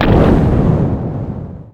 jetpack.wav